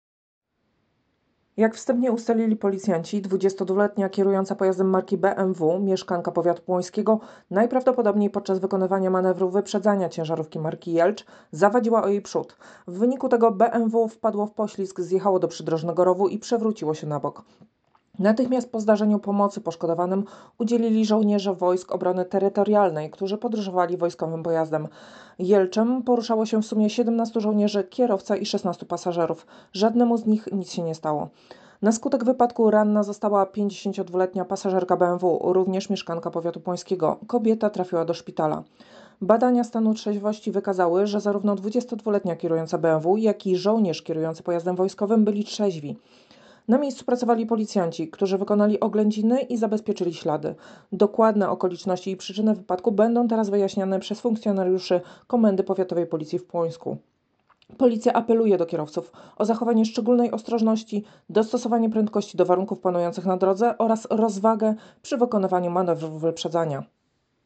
Nagranie audio Wypowiedź nadkom.